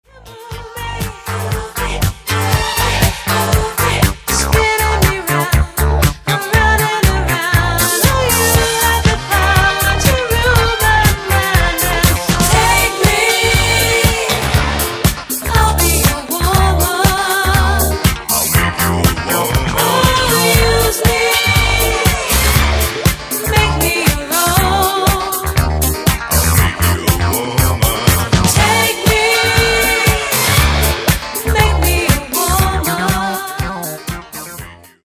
Remastered High Definition
Genere:   Disco